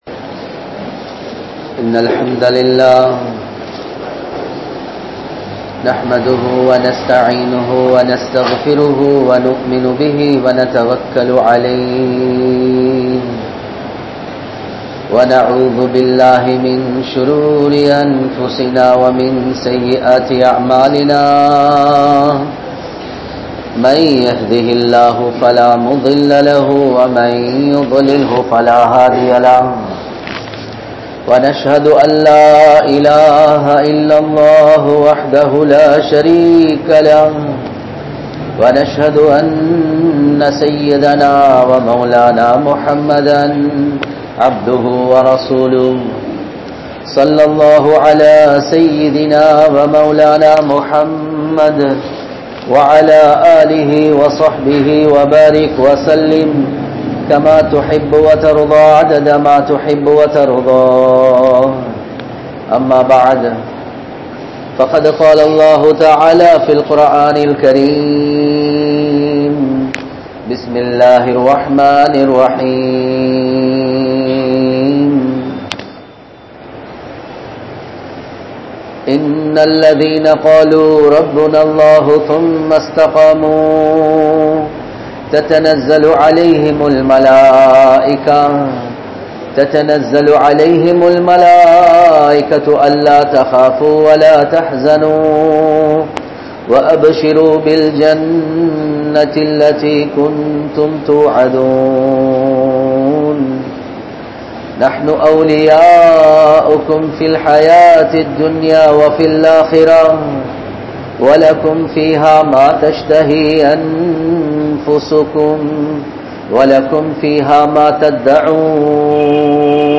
Vaalifaththai Paavaththil Kalikkatheerhal (வாலிபத்தை பாவத்தில் கழிக்காதீர்கள்) | Audio Bayans | All Ceylon Muslim Youth Community | Addalaichenai
Safa Jumua Masjidh